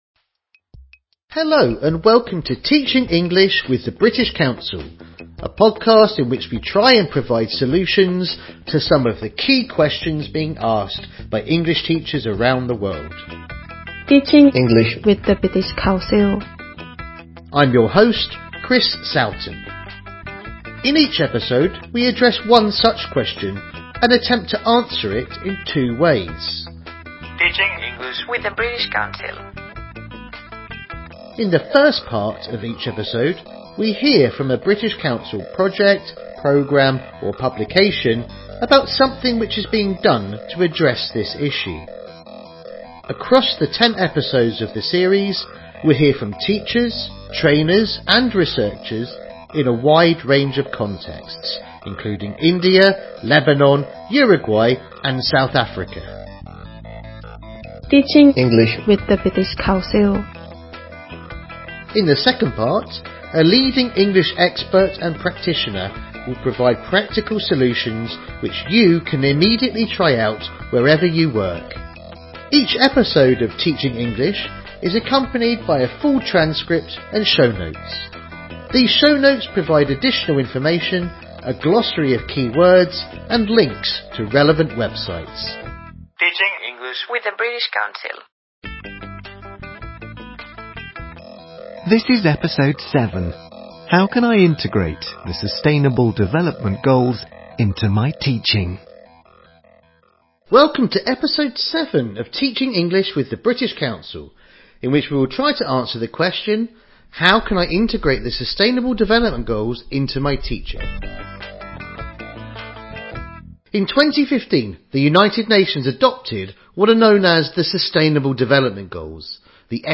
British Council: TeachingEnglish - series overview In this ten-part podcast series from the British Council, we try and provide solutions to some of the key questions being asked by English teachers around the world. Each episode explores a specific topic through interviews, a focus on recent developments and reports on British Council initiatives in English language teaching.